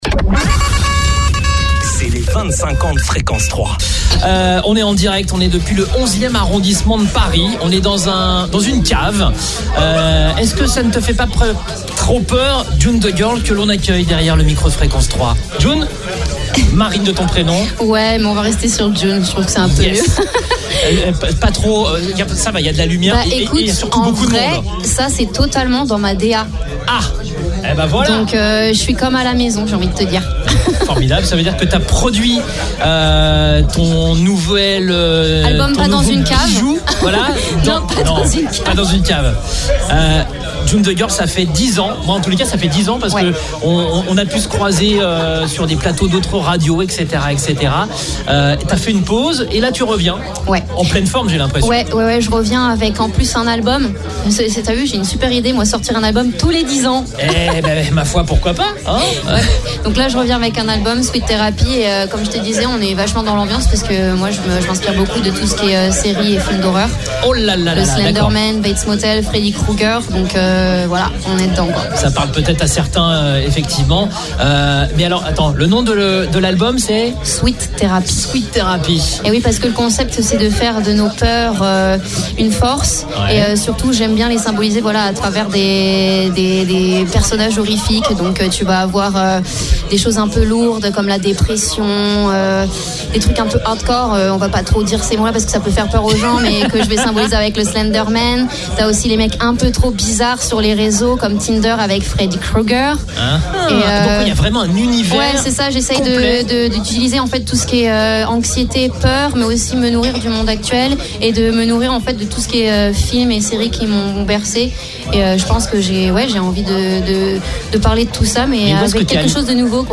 L’artiste, qui signe son grand retour après quelques années d’absence, nous a accordé une interview exclusive pour parler de son nouvel album « Sweet Therapy », un projet ancré dans l’univers de l’horreur.
Si le décor de l’interview, une cave parisienne, collait parfaitement à l’ambiance